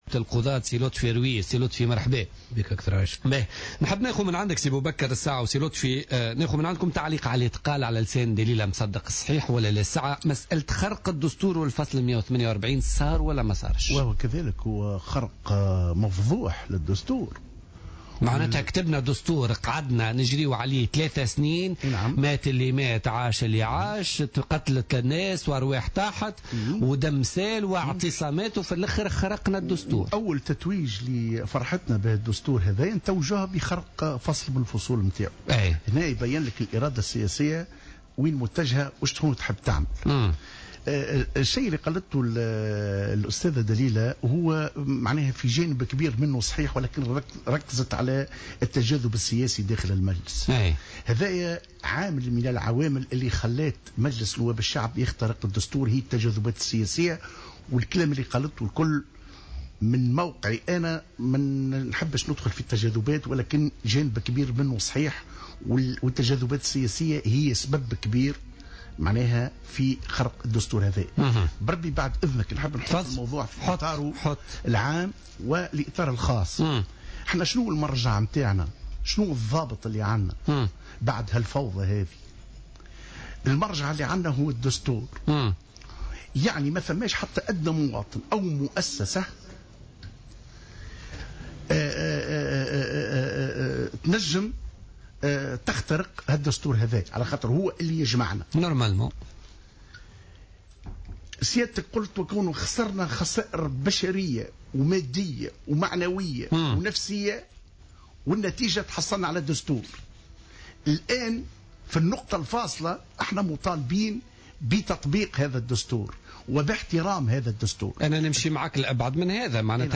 ضيف بوليتيكا